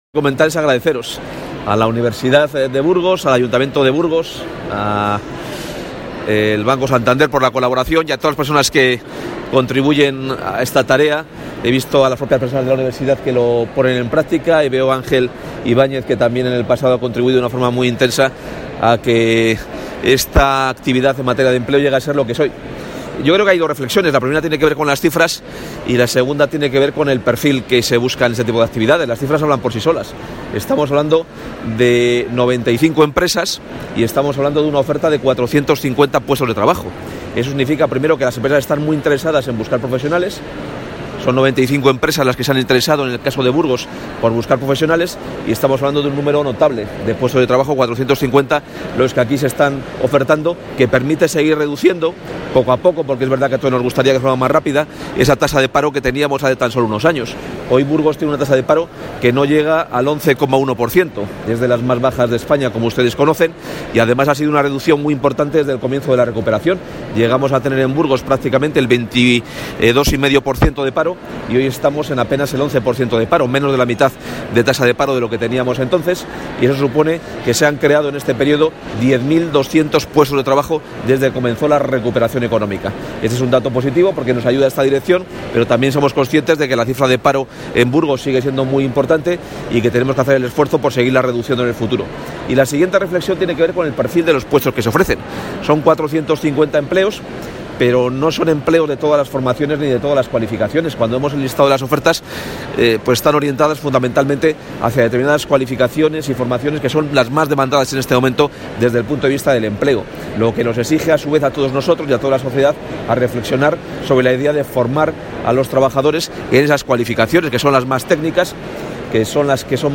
El consejero de Empleo, Carlos Fdez. Carriedo, ha participado hoy en la inauguración del XVII Foro de Empleo de la Universidad de Burgos.